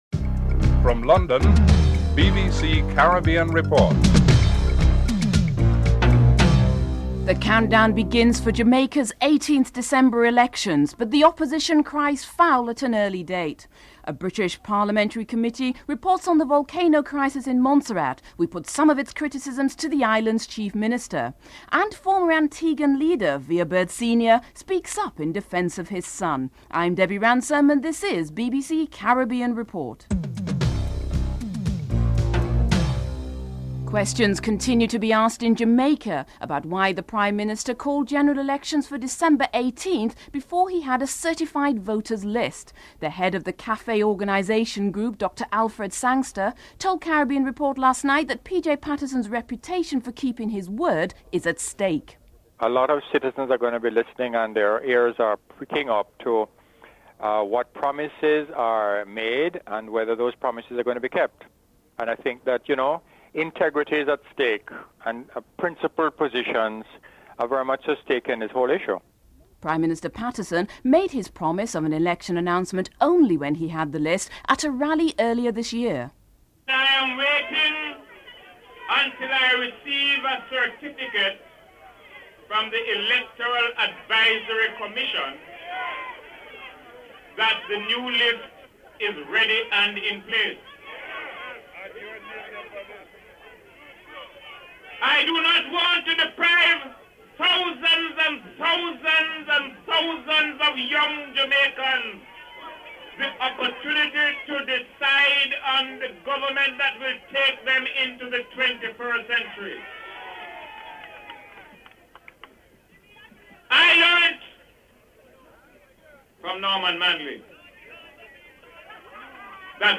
3. A British Parliamentary Committee reports on the volcano crisis in Montserrat and we put some of its criticisms to the island's Chief Minister. Bowen Wells, Chairman of the British Parliamentary Committee responsible for Montserrat, Chief Minister David Brandt and Prime Minister of St Lucia Kenny Anthony are interviewed (04:49-12:01)
4. Former Antiguan leader Vere Bird Sr. speaks up in defence of his son Vere Bird Sr. and Lester Bird are interviewed (12:02-15:27)